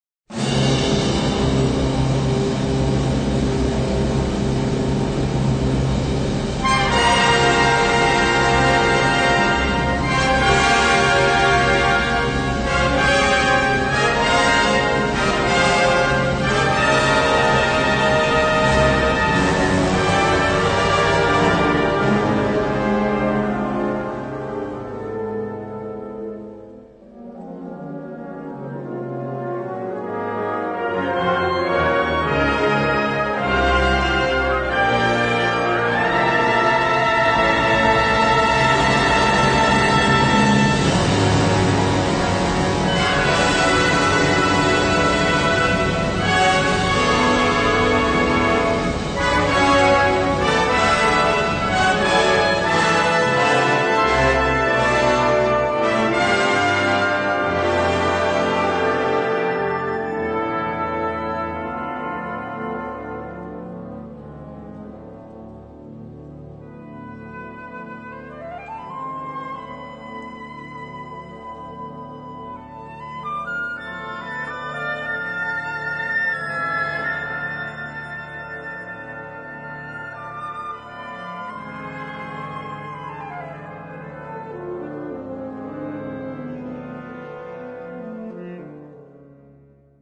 harmonieorkest